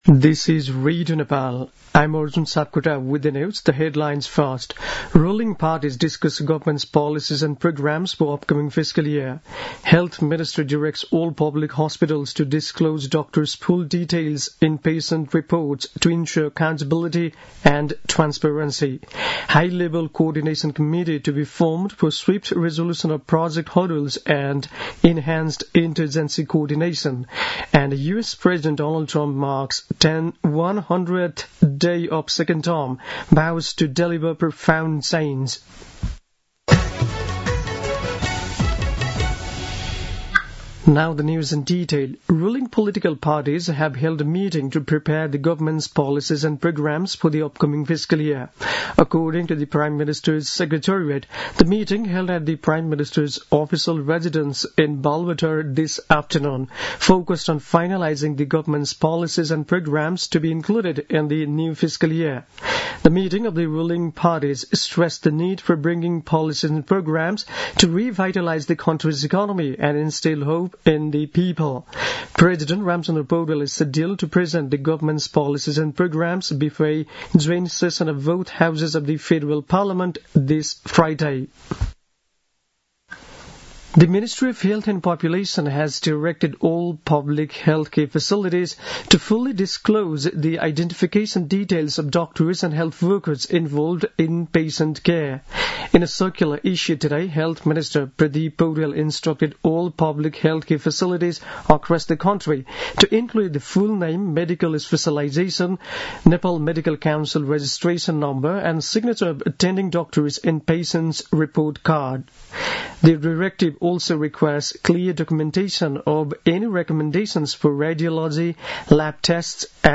दिउँसो २ बजेको अङ्ग्रेजी समाचार : १७ वैशाख , २०८२
2-pm-news-1-13.mp3